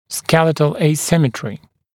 [‘skelɪtl eɪ’sɪmətrɪ][‘скелитл эй’симэтри]асимметрия лицевого отдела черепа